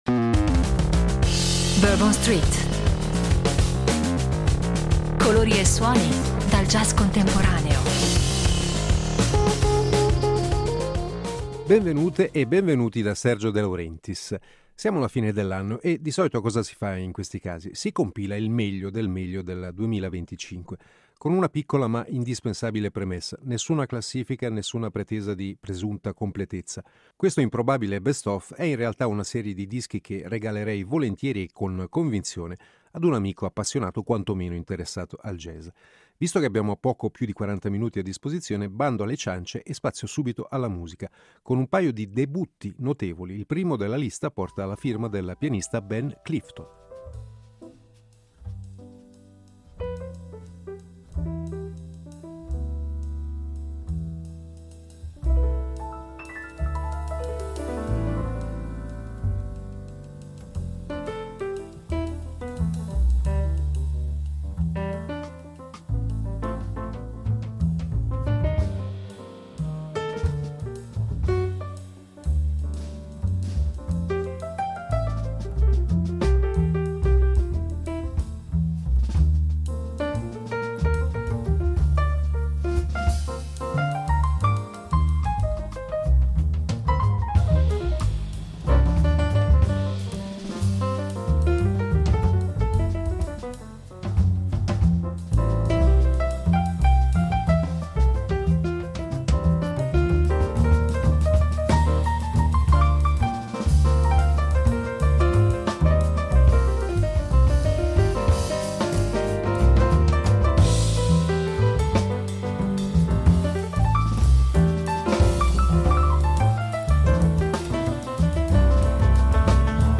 Il meglio del jazz per Natale: classici, fusion e sorprese
Sono consigli che spaziano in più ambiti e sottogeneri: si passa dalle riletture in chiave moderna del bebop e dell’hard bop a nuovi connubi tra jazz e sapori latini. Si va dalle atmosfere intime della classica formazione in trio, alle sonorità sgargianti delle orchestre.